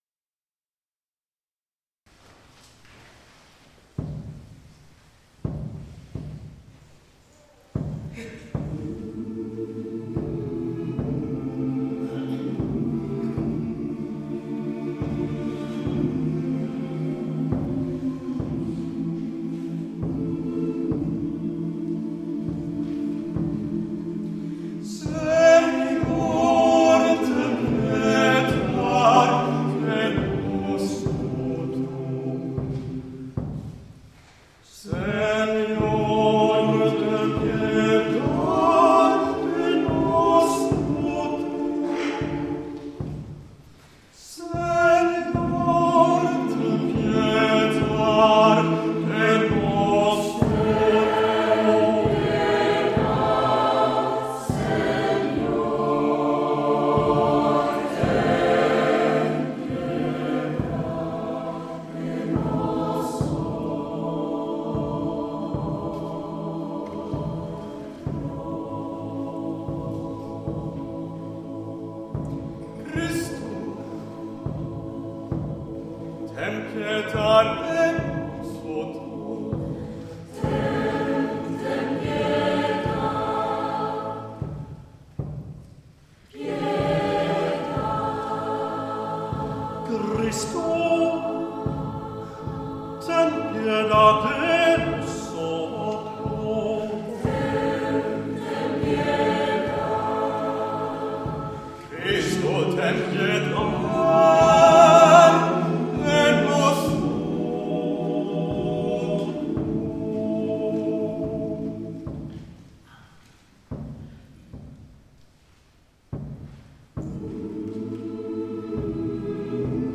Nyårskonsert 2015 Ängelholms kyrka